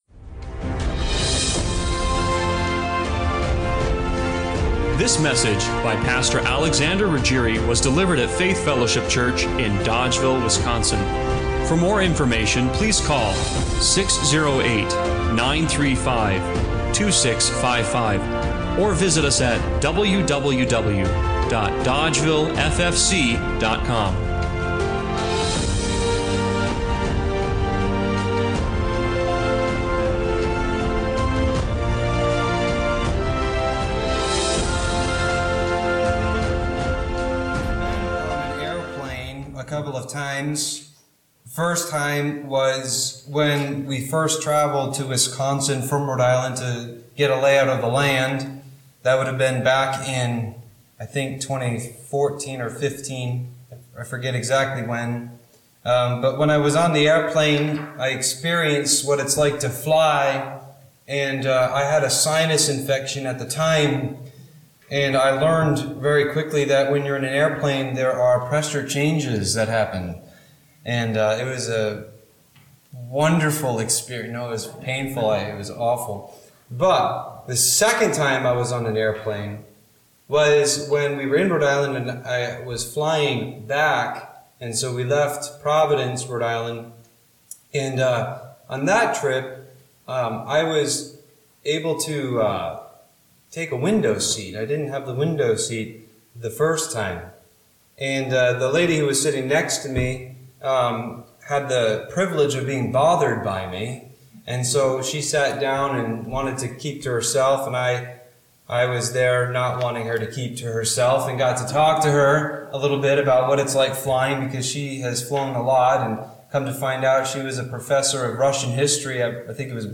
John 1:1-18 Service Type: Sunday Morning Worship How do you reach a God who dwells in unapproachable light?